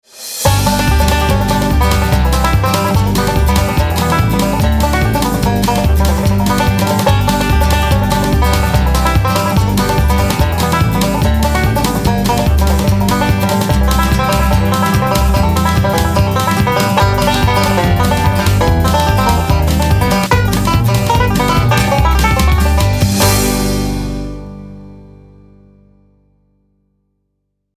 Band